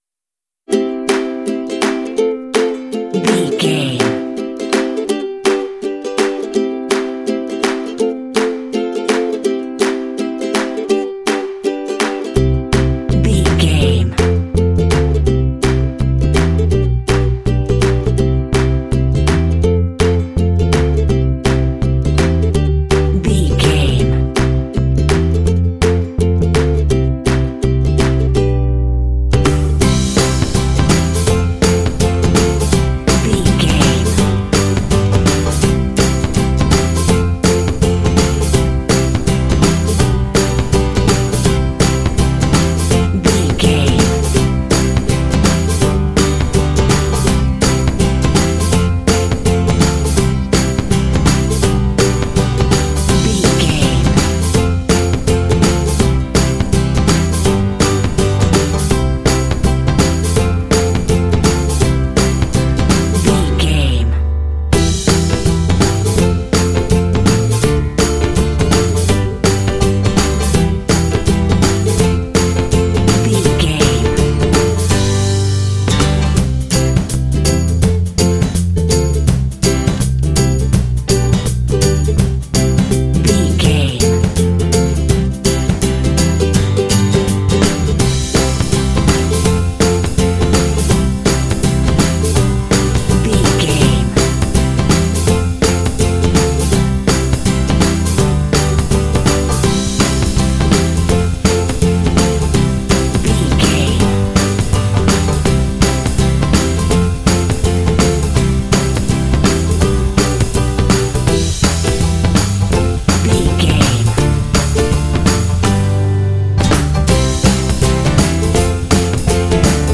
Uplifting
Ionian/Major
joyful
energetic
drums
percussion
acoustic guitar
bass guitar
indie
pop
contemporary underscore